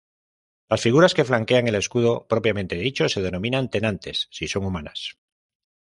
pro‧pia‧men‧te